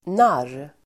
Uttal: [nar:]